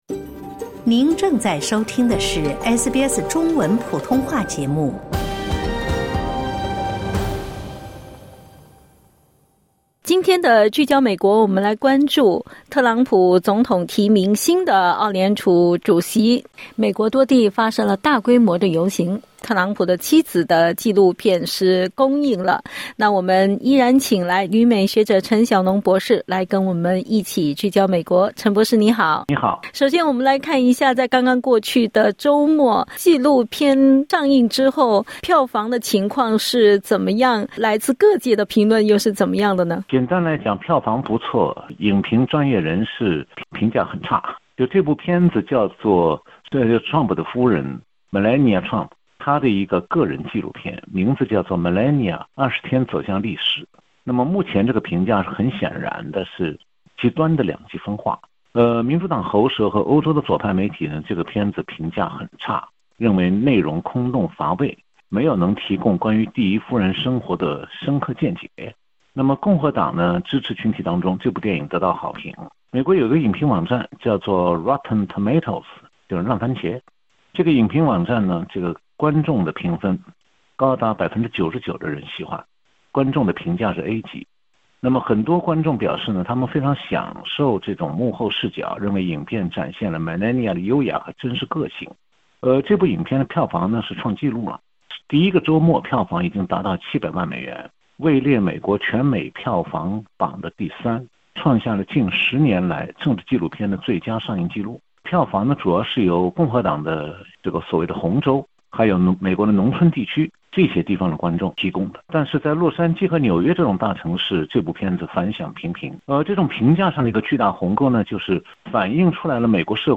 点击音频收听详细采访 采访内容仅为嘉宾观点 欢迎下载应用程序SBS Audio，订阅Mandarin。